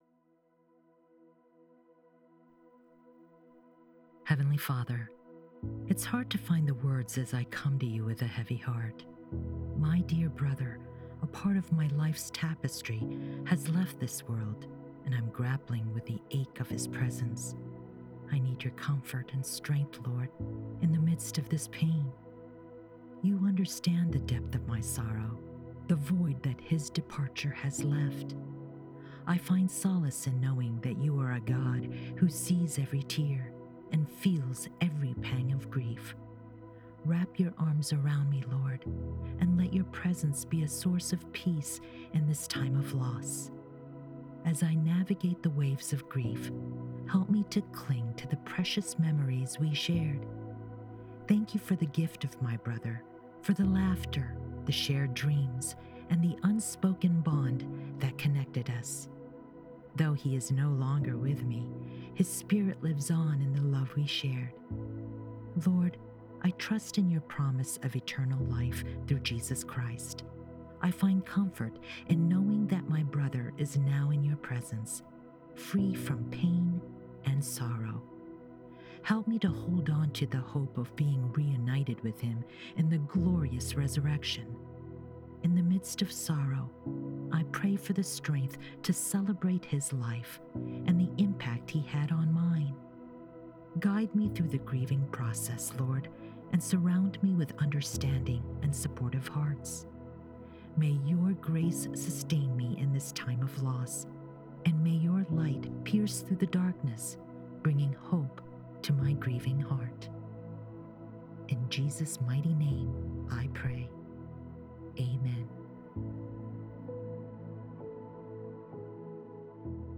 In this sacred space, know that you are not alone, and may these whispered words gently cradle your spirit, bringing a measure of peace to your grieving heart.